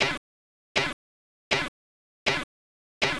chaffflair.wav